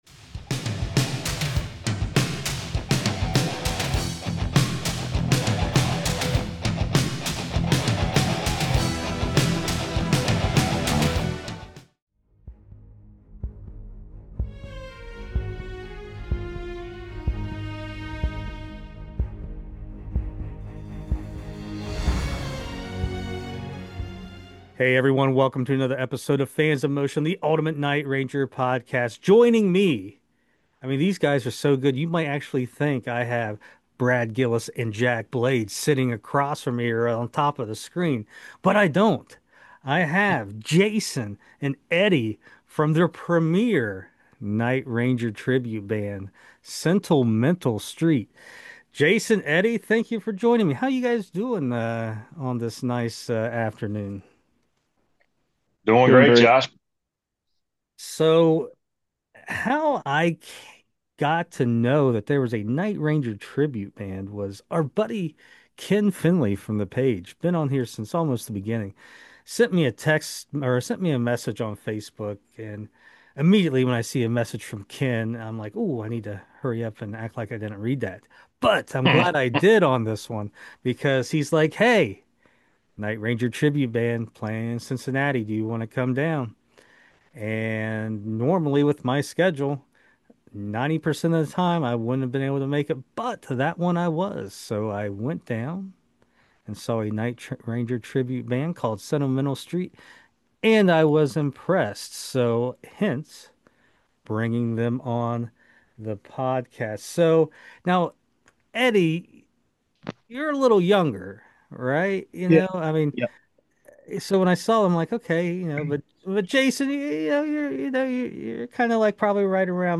Fans In Motion Episode 174: Interview With Night Ranger Tribute Band “Sentimental Street”!